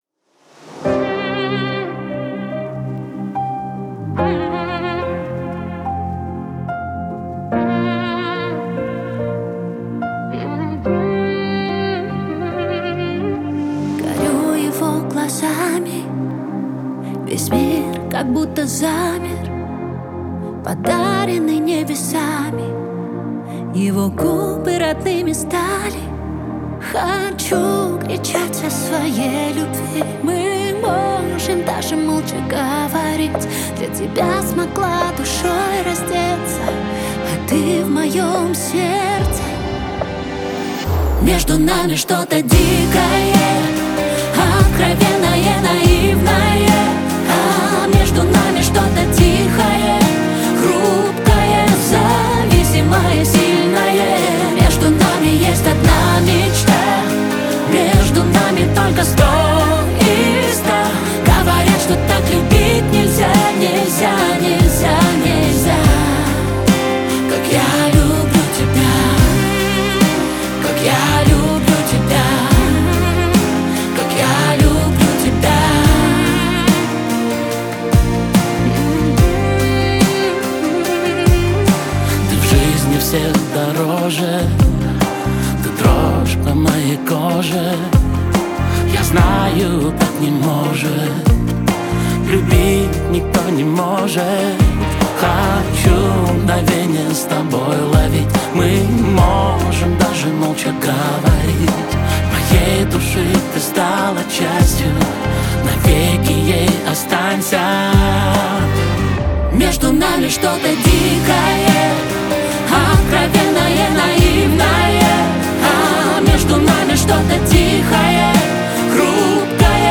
Качество: 320 kbps, stereo
Классическая